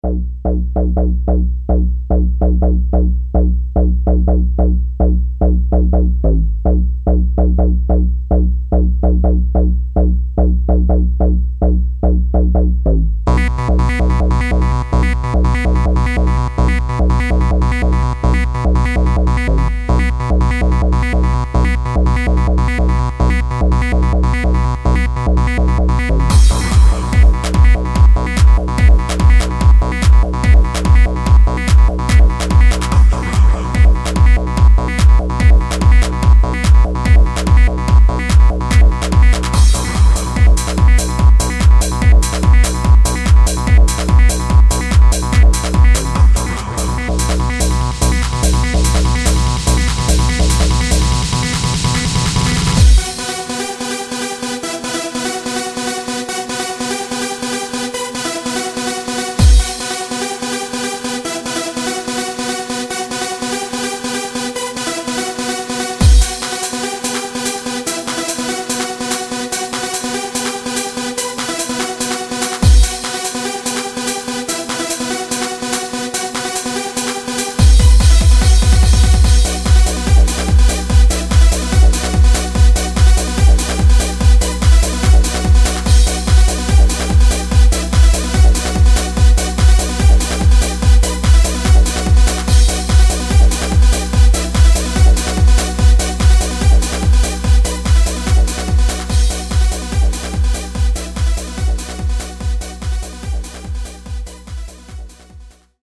Preview: Strange style electronic musical sequence.